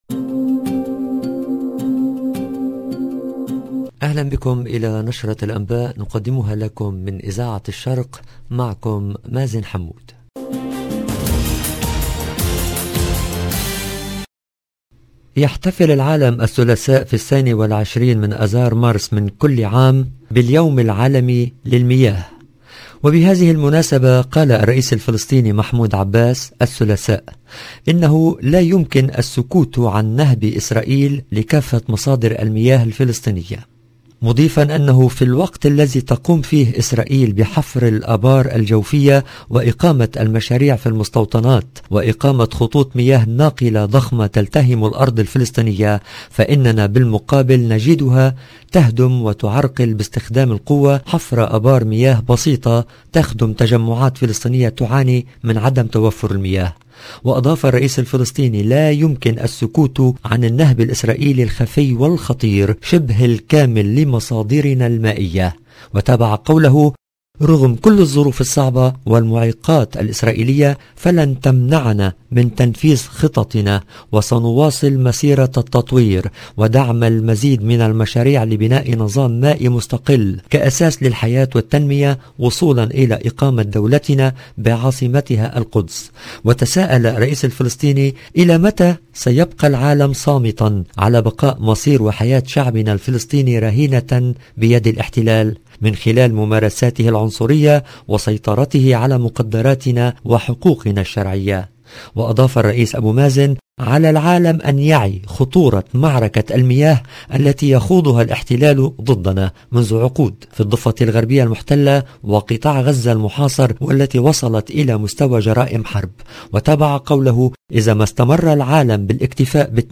LE JOURNAL DU SOIR EN LANGUE ARABE DU 22/03/22